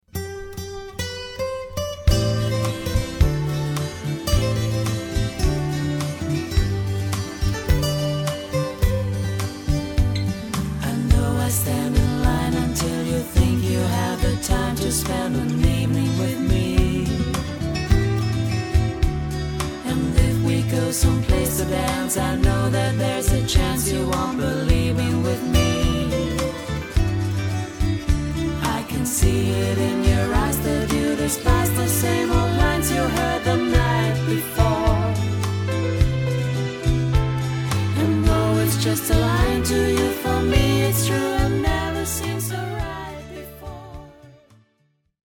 Sängerin, Sänger, Gitarre/Gesang, Bass/Keyboard, Schlagzeug